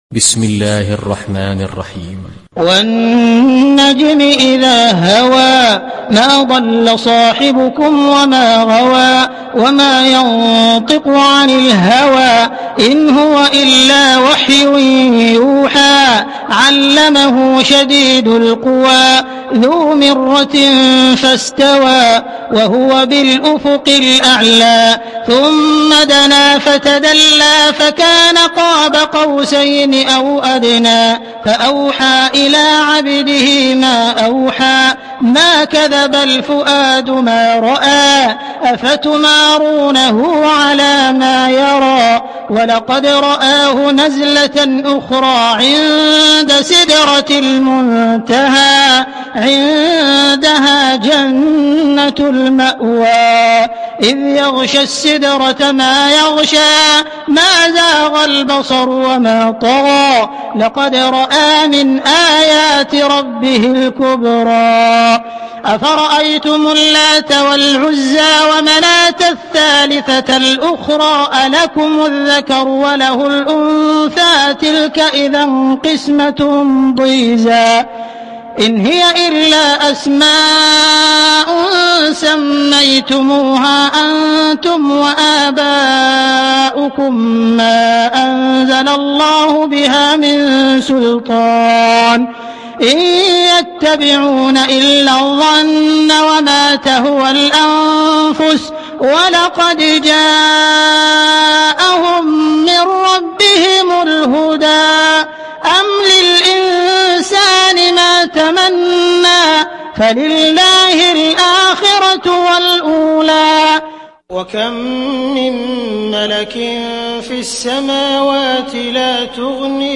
تحميل سورة النجم mp3 بصوت عبد الرحمن السديس برواية حفص عن عاصم, تحميل استماع القرآن الكريم على الجوال mp3 كاملا بروابط مباشرة وسريعة